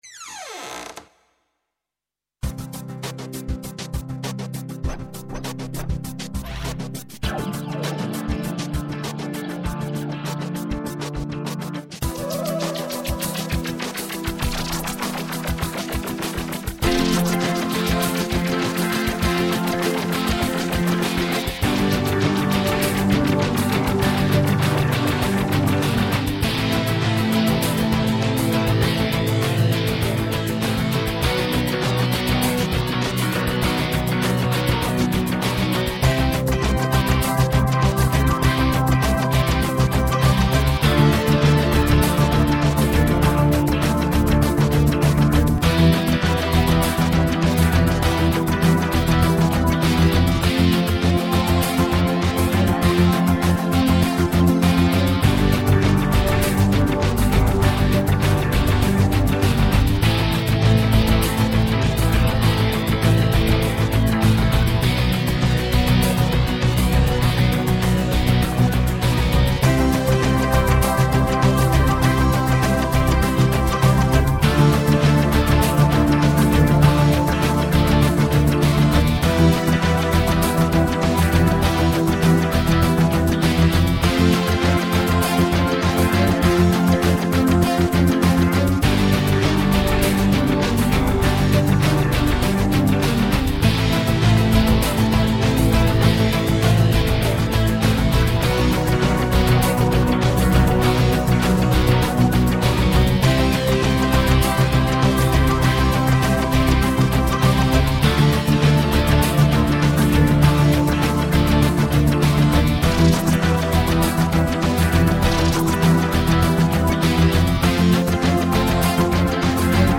Gros (slow) 3.21 min.
is almost rockmusic
and is a slower version.